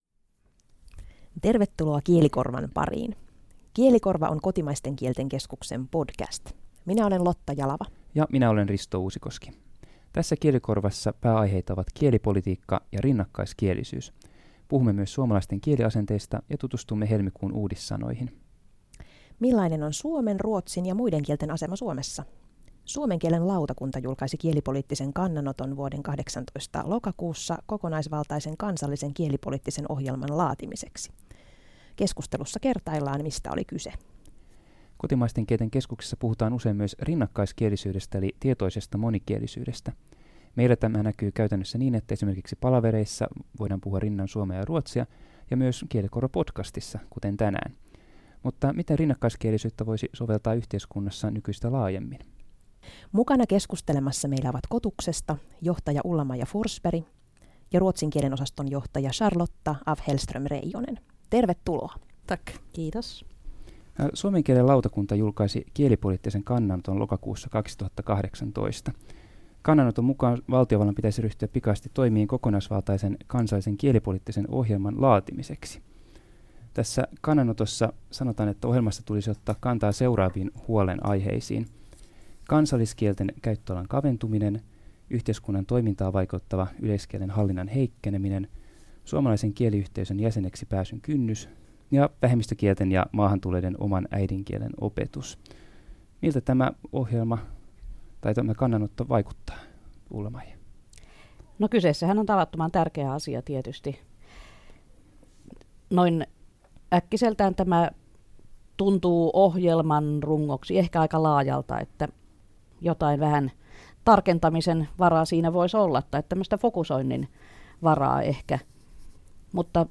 Kielikorva-podcastissa keskustellaan kielipolitiikasta ja Kalevalan päivän leivoksesta sekä auervaarasta.